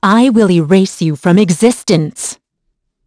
Valance-Vox_Skill6.wav